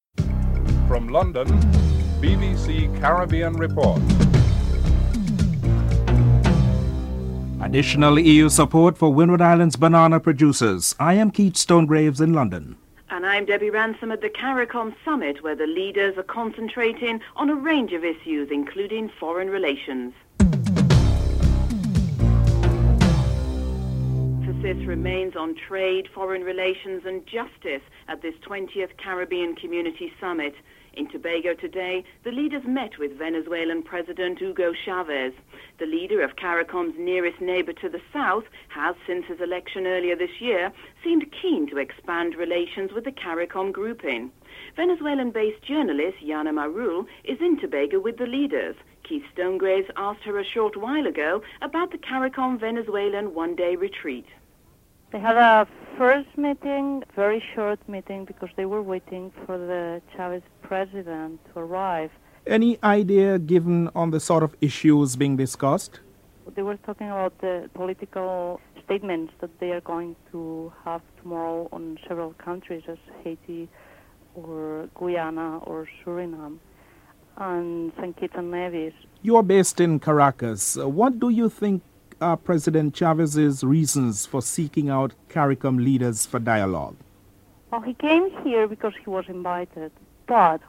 1. Headlines with anchors